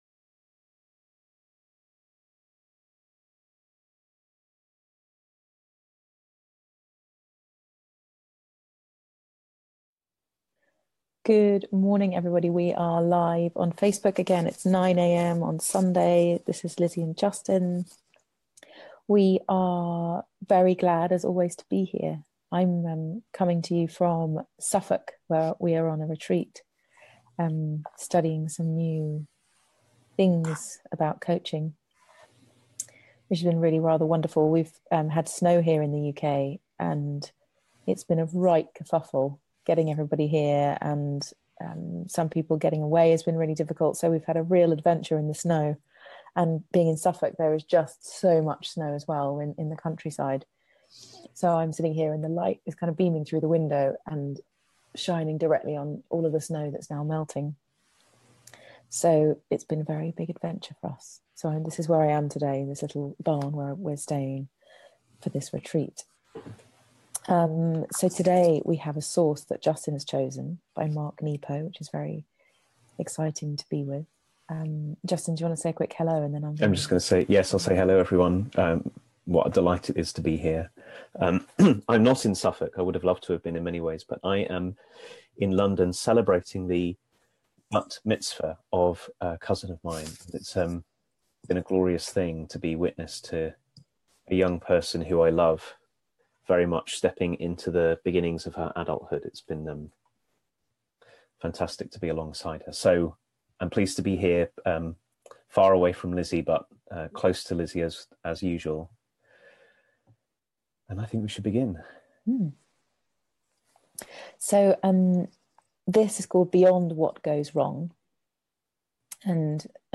What is there beyond 'what goes wrong'? The possibility that to be human is to be a kind of depth itself. A conversation